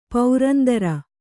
♪ paurandara